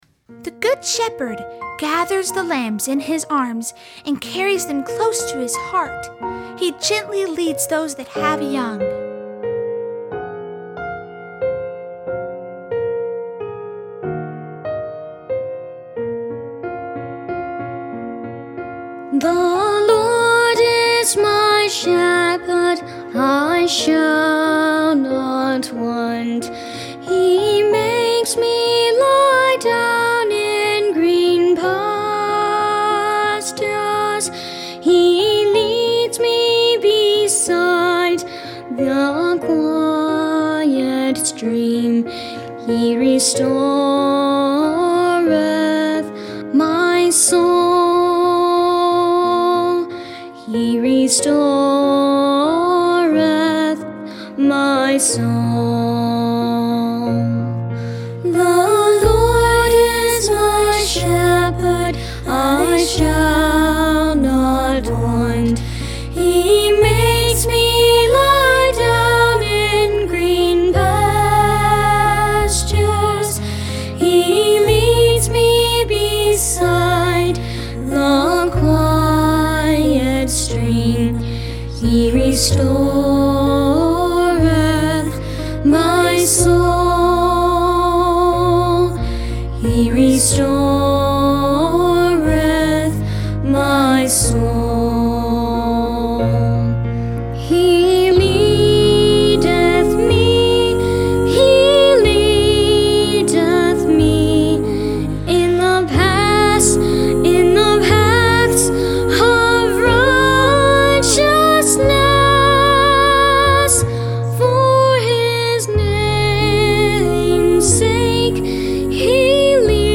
Unison with piano